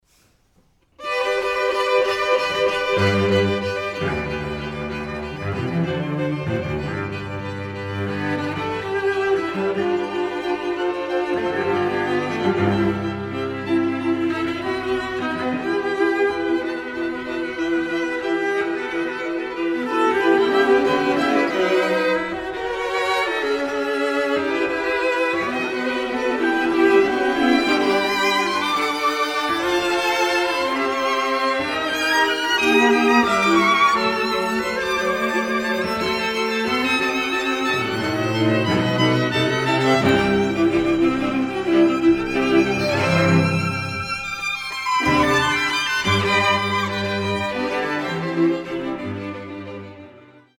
2nd International Chamber Music Festival – Tuscany